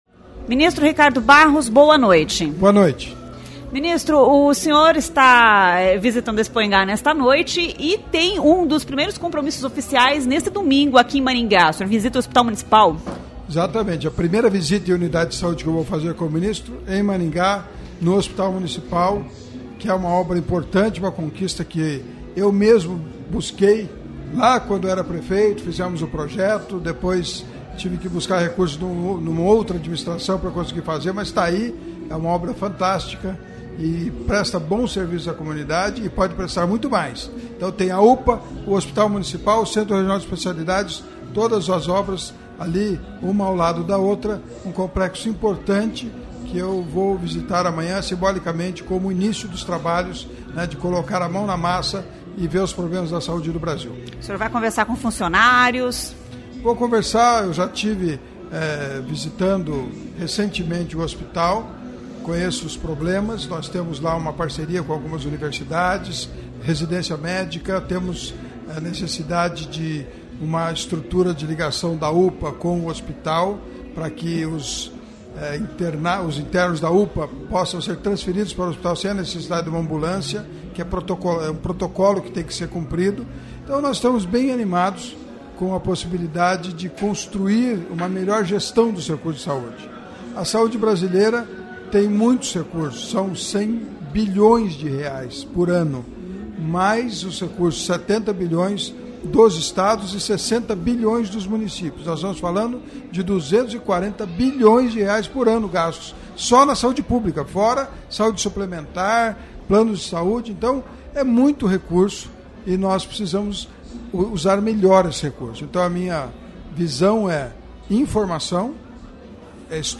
Ministro da Saúde visita Expoingá e concede entrevista exclusiva no estúdio da CBN no parque de exposições